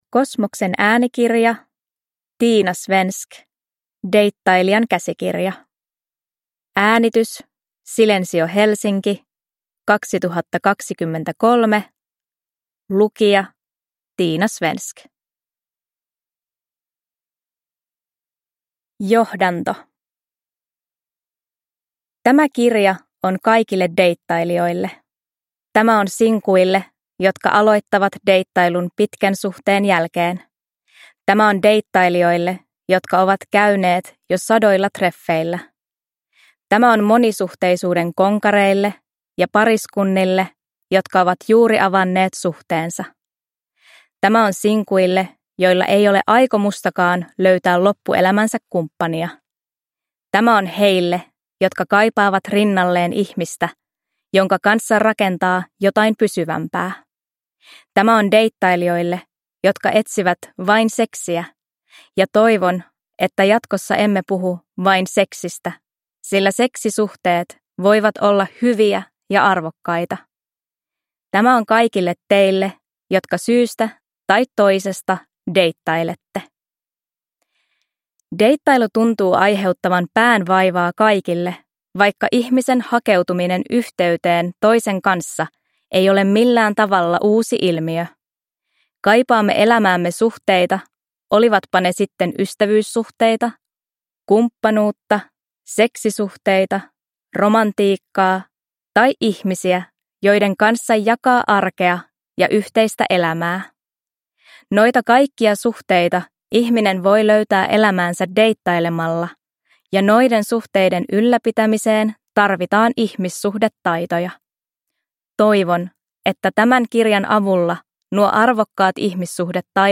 Deittailijan käsikirja – Ljudbok – Laddas ner